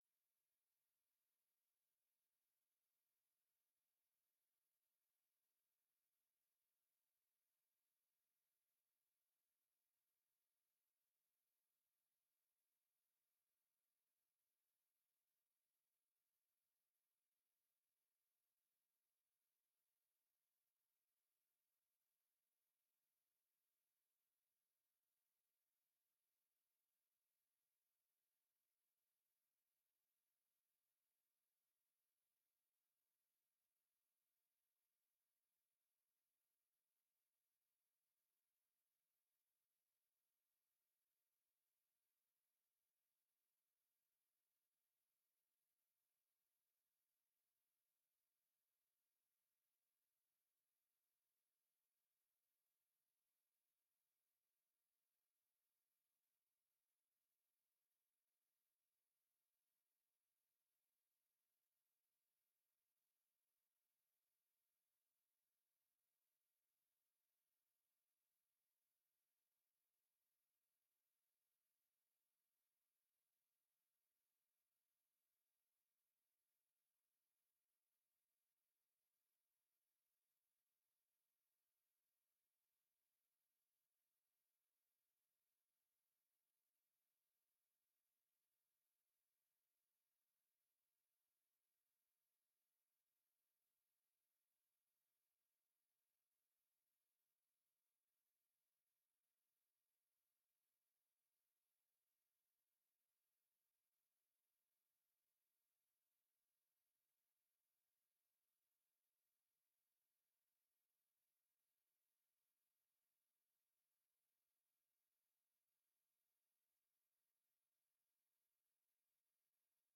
Commissie Burger en bestuur 13 juni 2024 19:30:00, Gemeente Ouder-Amstel
Download de volledige audio van deze vergadering